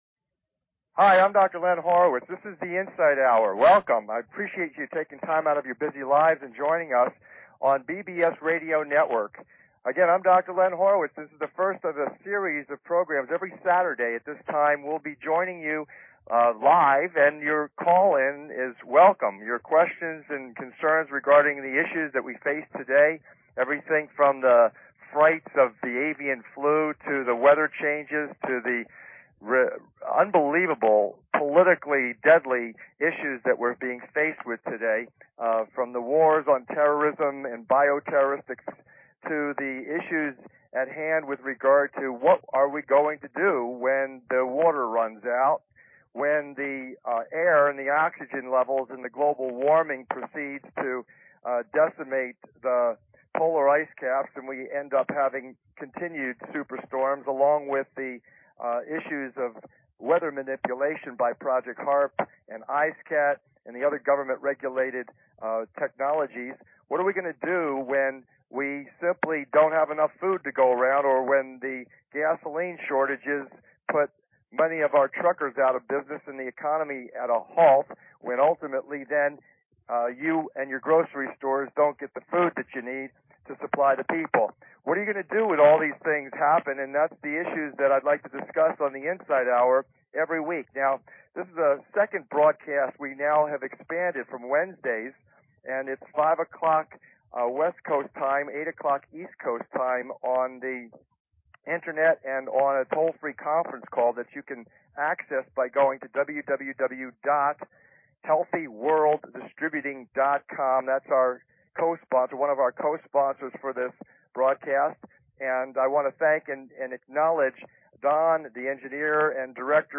The Debut of The Insight Hour talk radio show, November 5, 2005